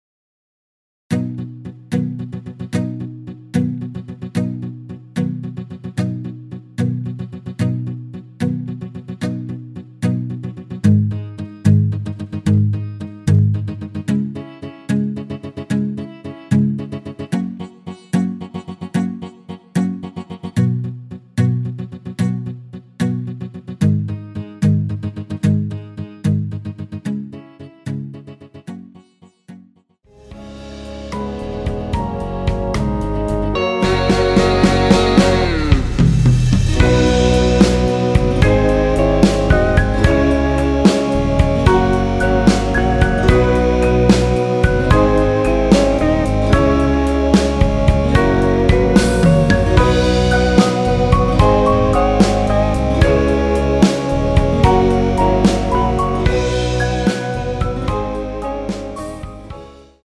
원키에서(+4)올린 MR입니다.
앞부분30초, 뒷부분30초씩 편집해서 올려 드리고 있습니다.